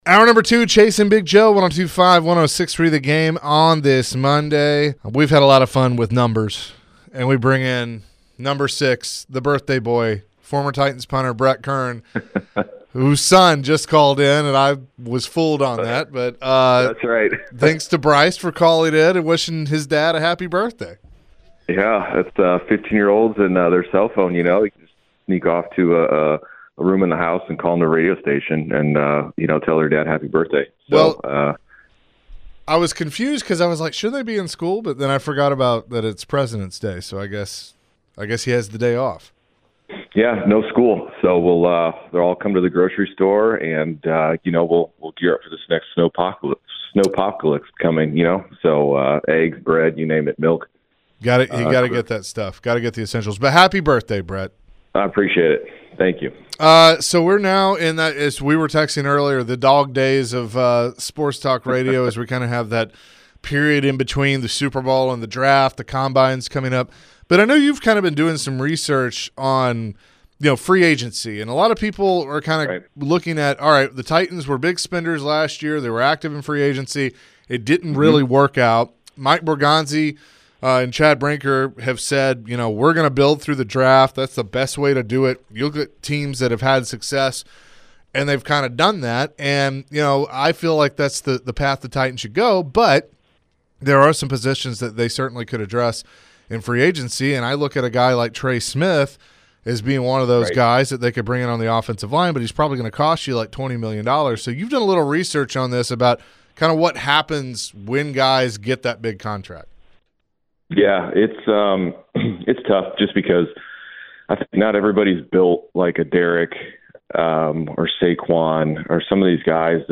Titans Punter Brett Kern joined the show and shared his thoughts on some offseason moves the Titans might need to look into. Plus, Brett celebrated his birthday with the guys on the show.